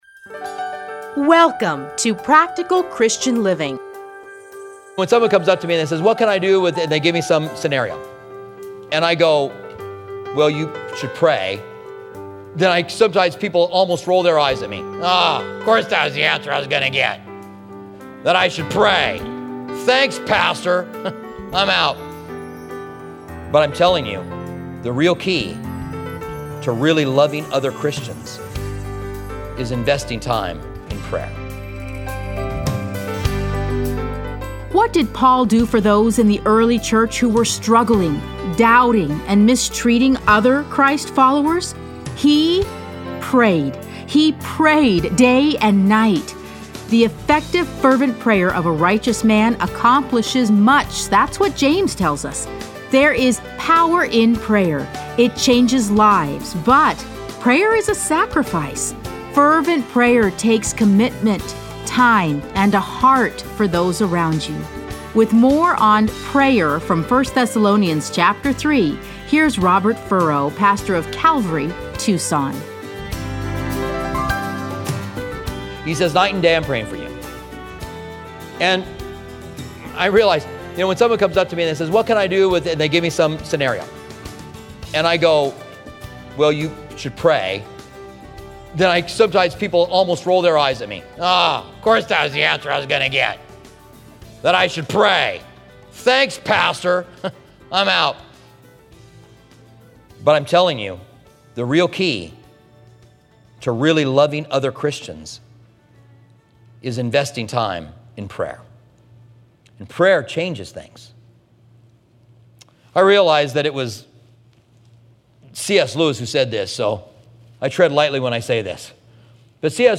Listen to a teaching from 1 Thessalonians 3:1-13.